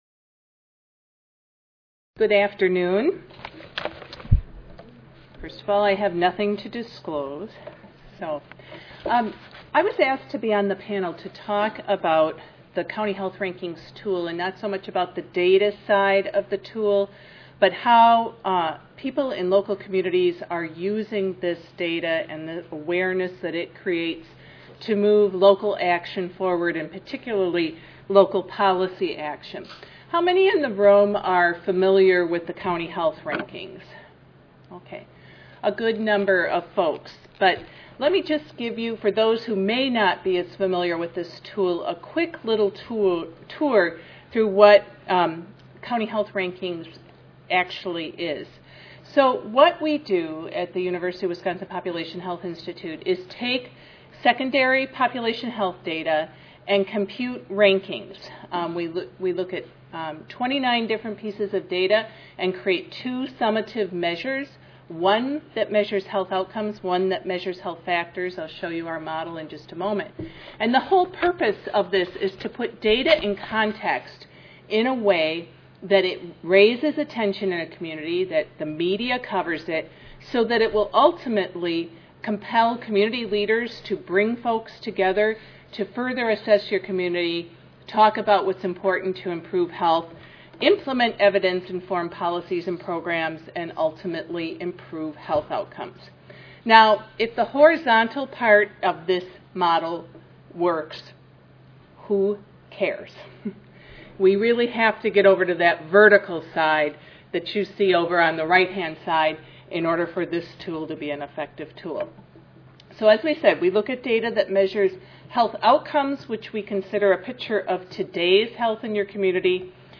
3314.0 Mobilizing a Public Health Campaign Monday, October 29, 2012: 2:30 PM - 4:00 PM Oral Panelists will share their perspectives on the best ways to engage the public, lawmakers, the media, and partners in support of a public health issue, giving participants an overview of the different components to putting together a successful advocacy campaign, such as planning a strategy, developing a message, obtaining funding, and setting and achieving goals.